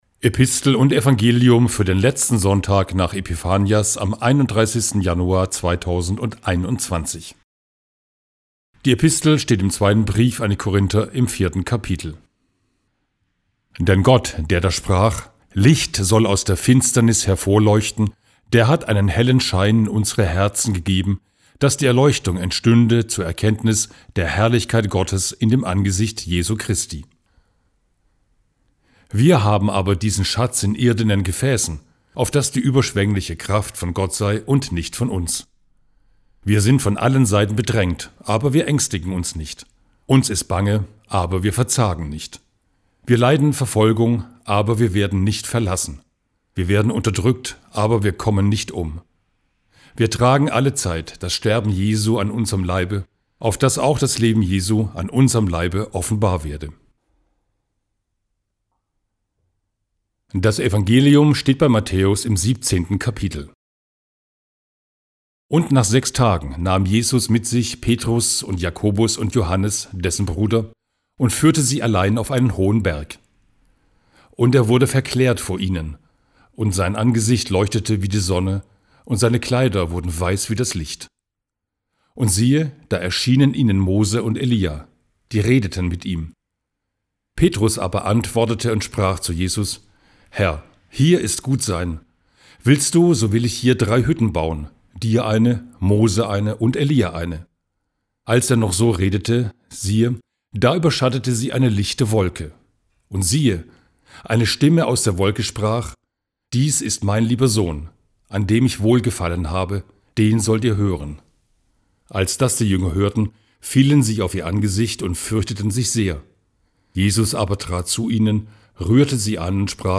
Gottesdienst 31.01.2021
Epistel und Evangelium